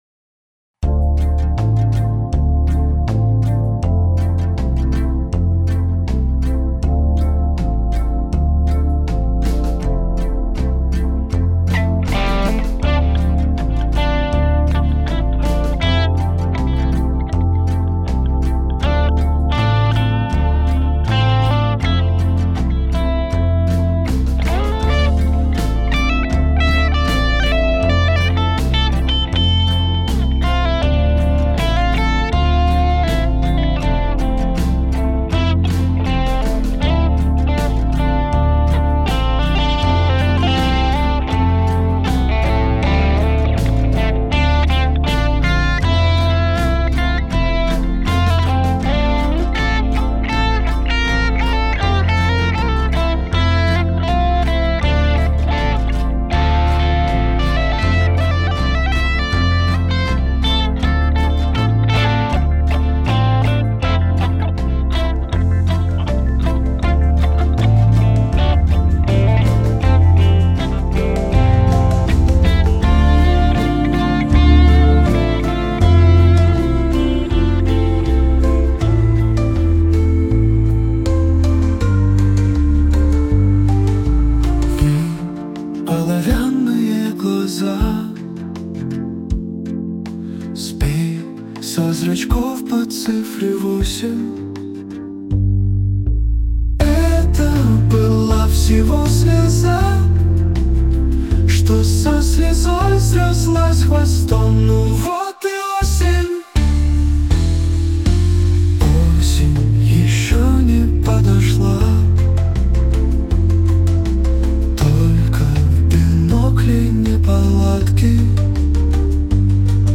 • Жанр: Фолк